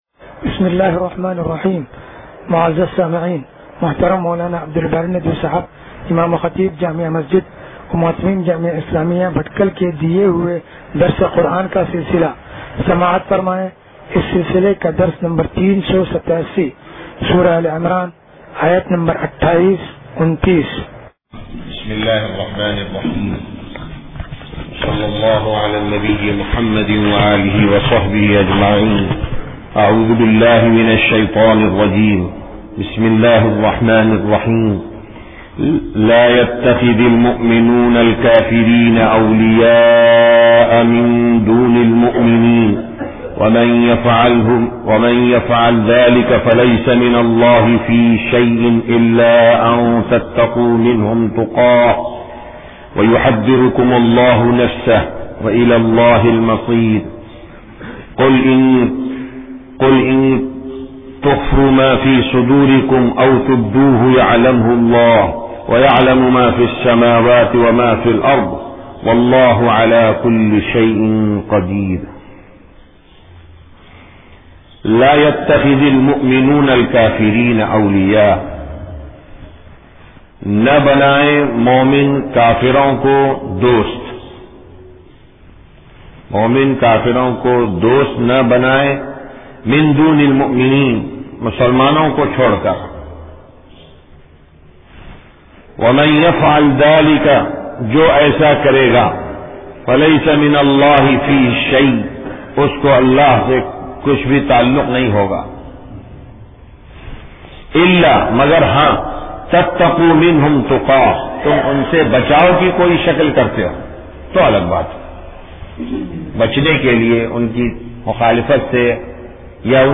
درس قرآن نمبر 0387